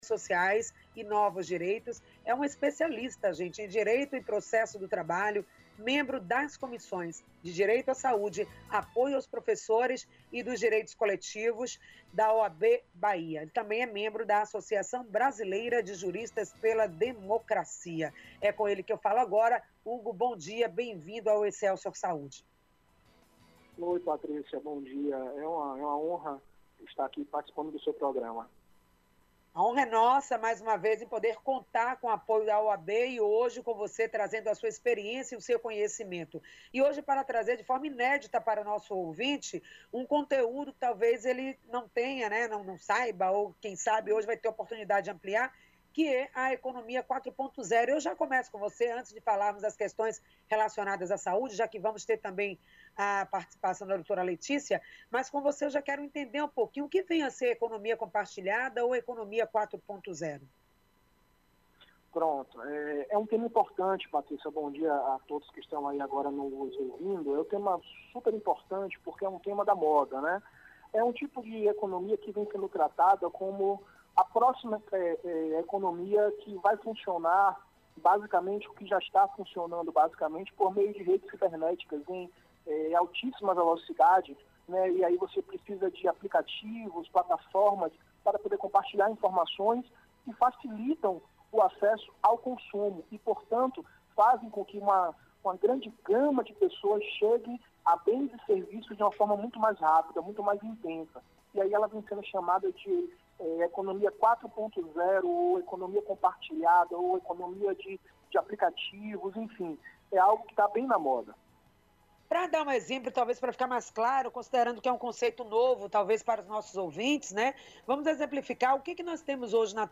entrevistou